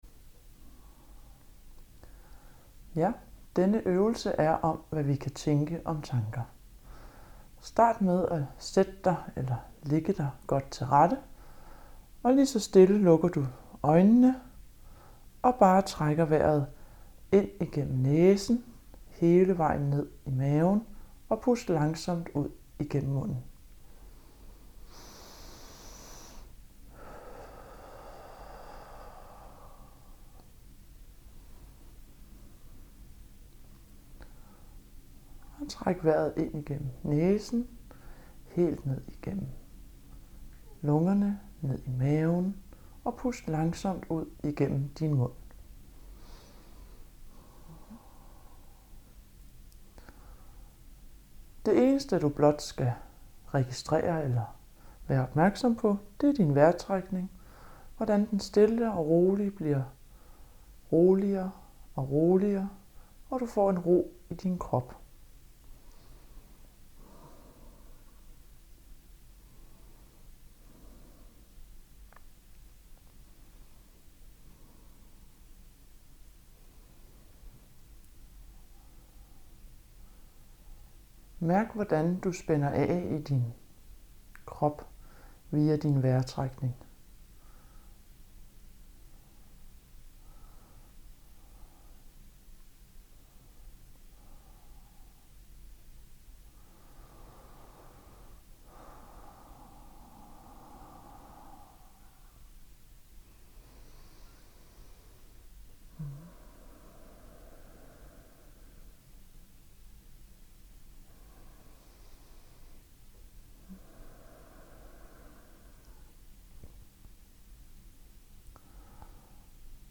Meditation på tanker